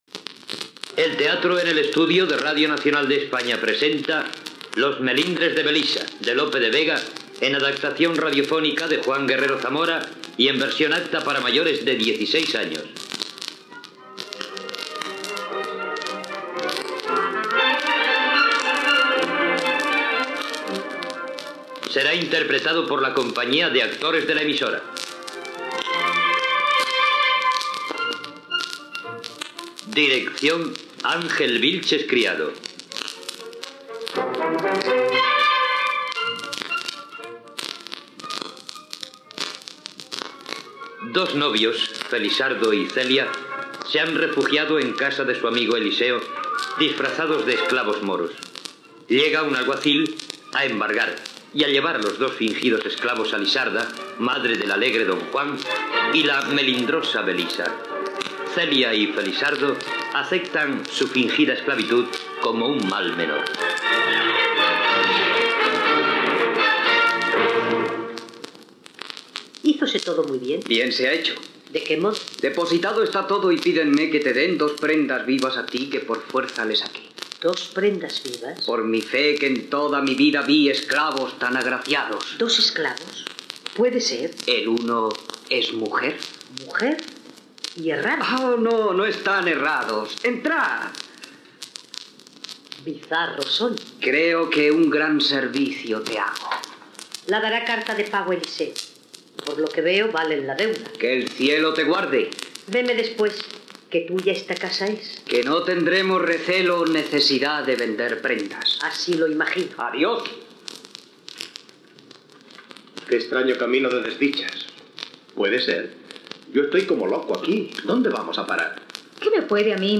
Careta del programa,adaptació radiofònica de "Los melindres de Belisa", de Lope de Vega. El narrador posa en situació de la trama i dels personatges.
Ficció